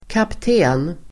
Uttal: [kapt'e:n]